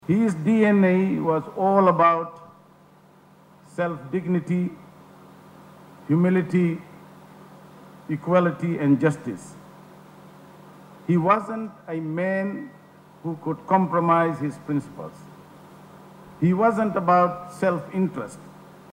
Delivering his eulogy also, Deputy Prime Minister Biman Prasad says that Patel was a man who would not compromise his principals.
In a solemn gathering at Vinod Patel ground in Ba, hundreds assembled to honor the memory of Patel.